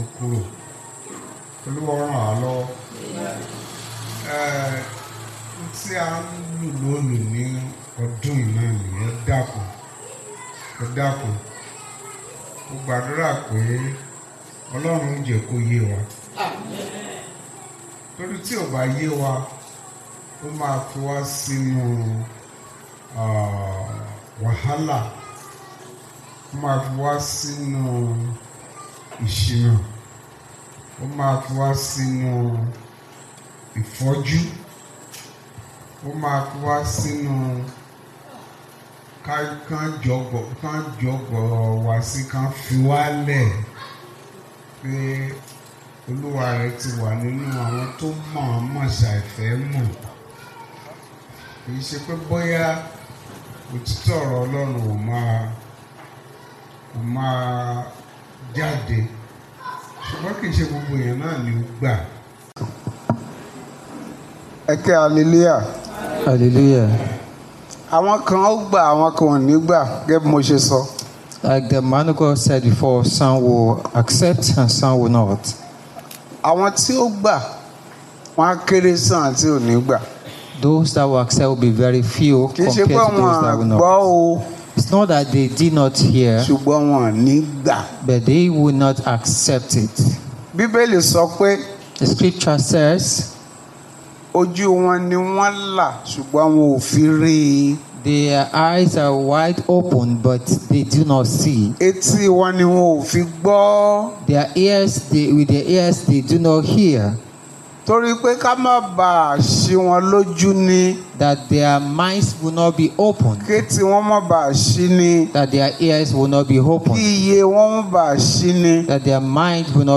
Wholesome Words Bible Class Passage: Matthew 22:23-32 Topics